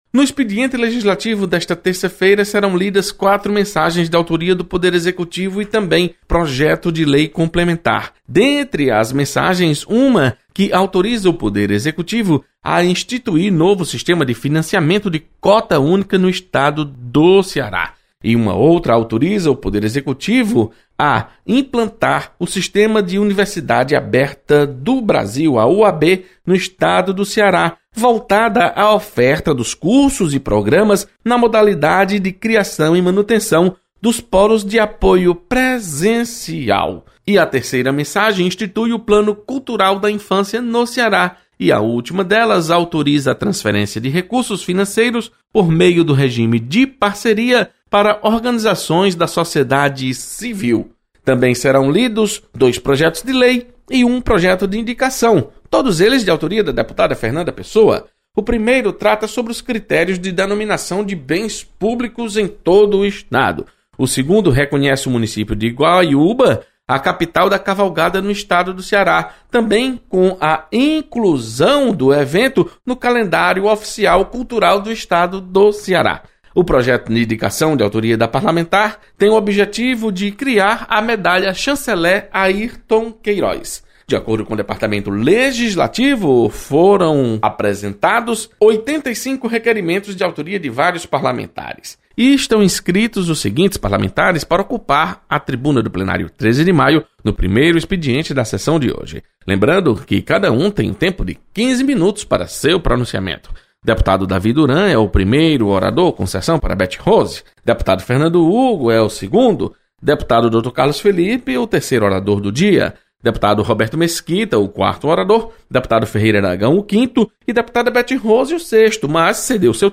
Acompanhe as informações do expediente legislativo desta terça-feira. Repórter